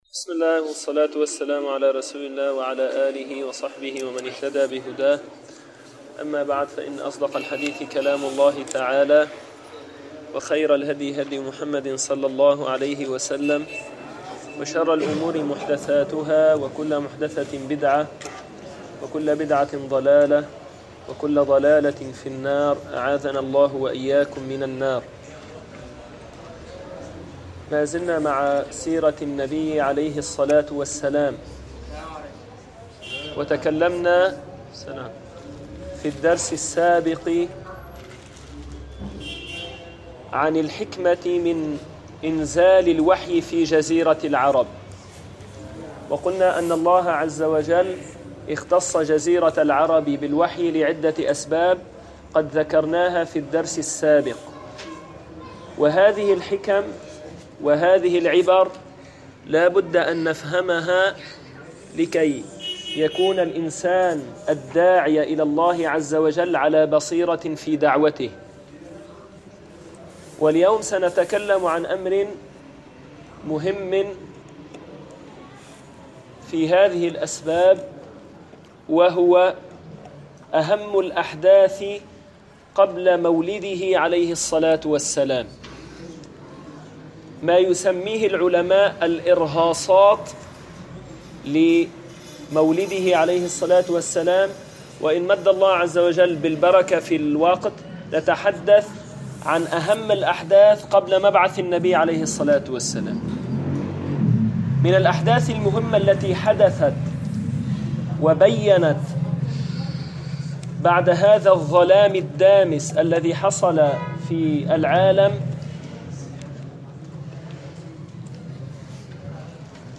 المكان: مسجد القلمون الغربي